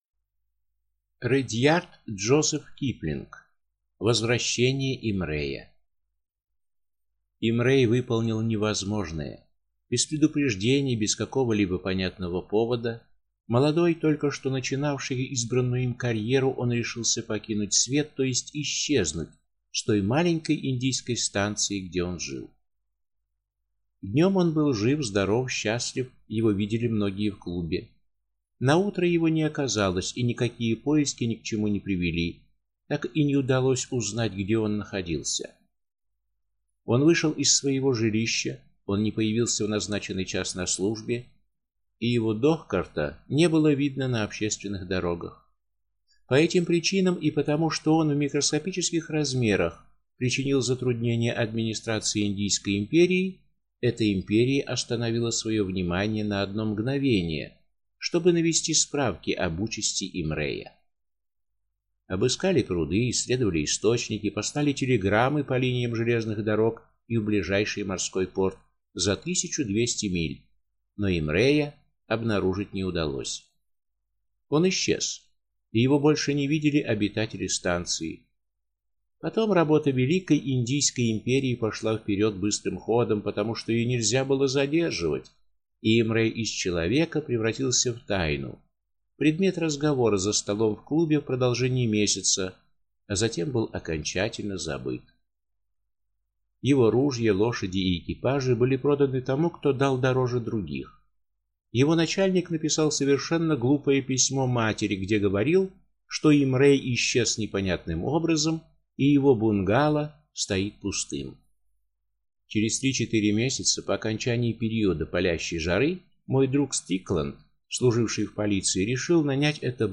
Аудиокнига Возвращение Имрея | Библиотека аудиокниг